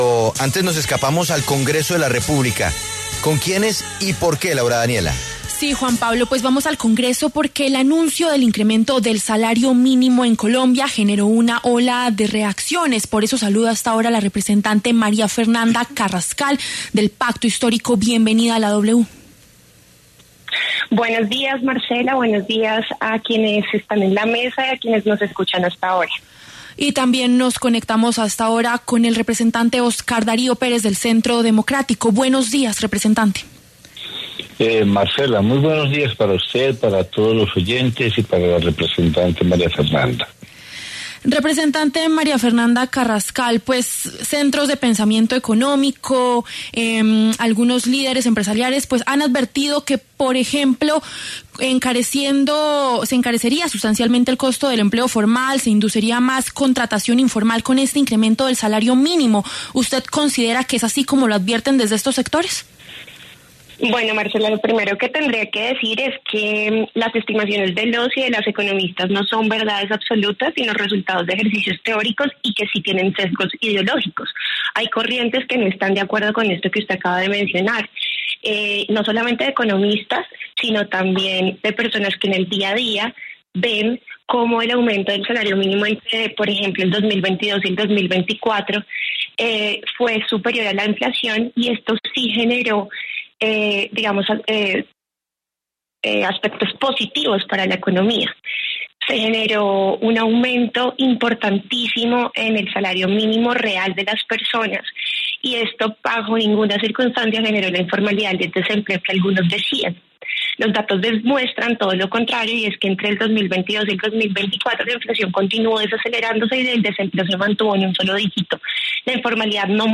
Los representantes María Fernanda Carrascal, del Pacto Histórico, y Oscar Darío Pérez, del Centro Democrático, hablaron en los micrófonos de La W.